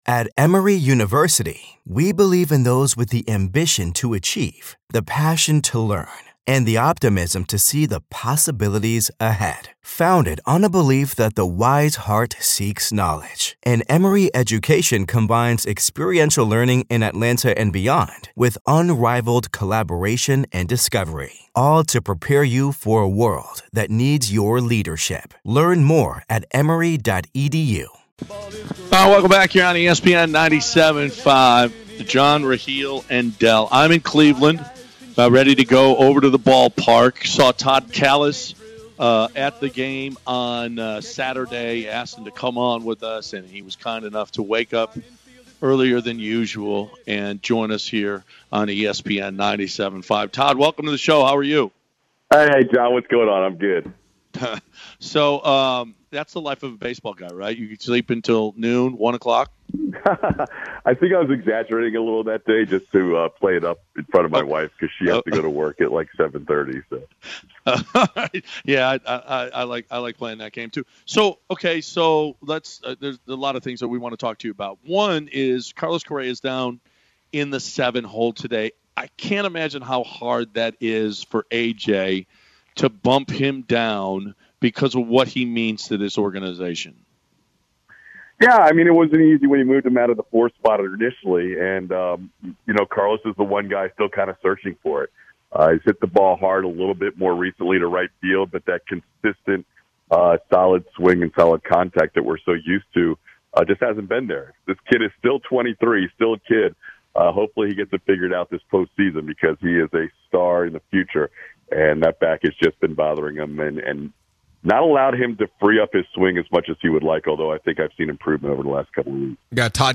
Interview
by phone